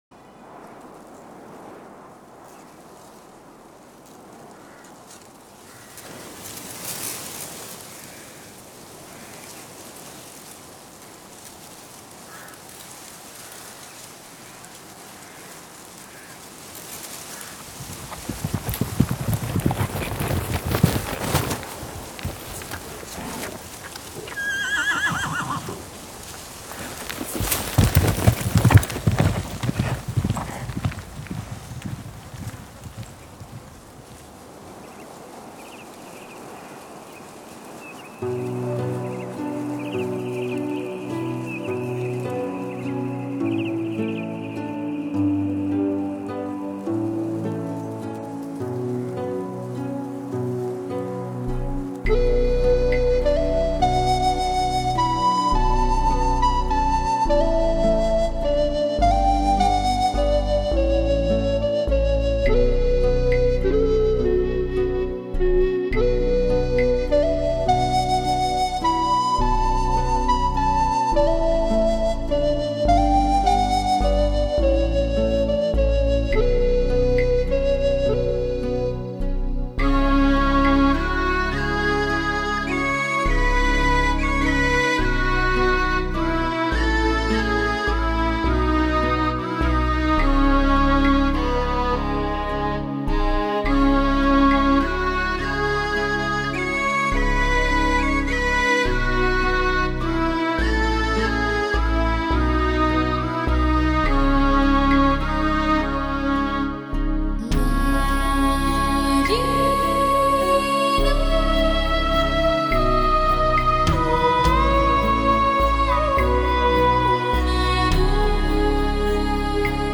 在类似于电影原声音碟中才有的市场的喧哗，马蹄的践踏，大雨、雷电和火堆创造的音效，海浪拍打岩礁中，而风声始终贯穿全碟！
而鼓乐和人声的合唱更添加了古色和陈迹，对于战争的残酷，人类的争执，远古的生存的活灵活现！